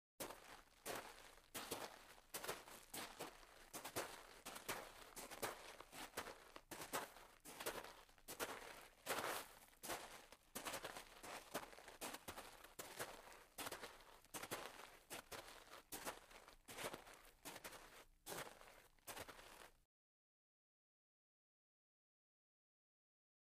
Footsteps On Gravel, Double